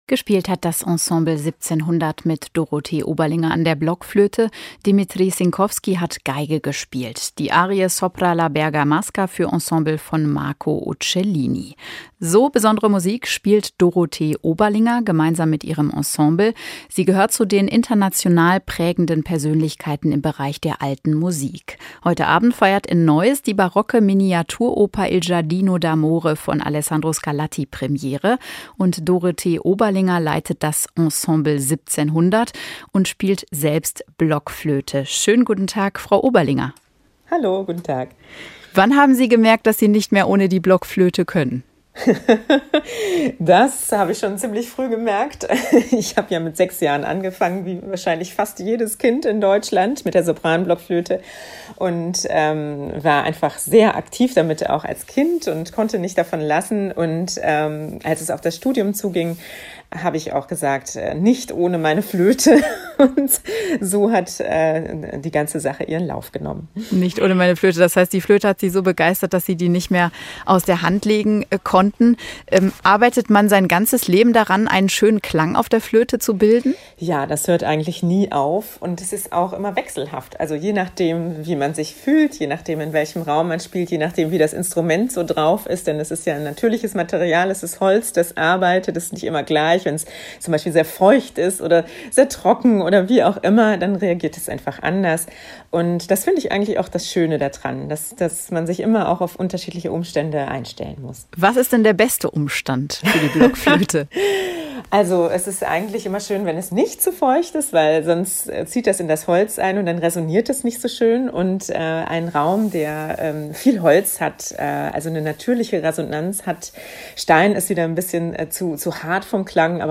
Im Gespräch: Die Blockflötistin Dorothee Oberlinger